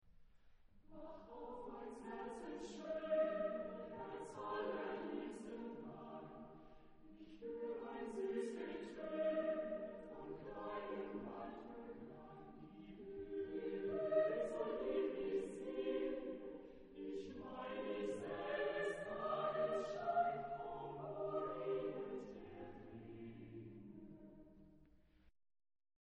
Genre-Style-Form: Secular ; Popular ; Folk music ; Romantic
Type of Choir: SATB  (4 mixed voices )
Tonality: F major
Discographic ref. : Internationaler Kammerchor Wettbewerb Marktoberdorf